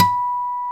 Index of /90_sSampleCDs/Roland L-CDX-01/GTR_Steel String/GTR_ 6 String
GTR 6-STR315.wav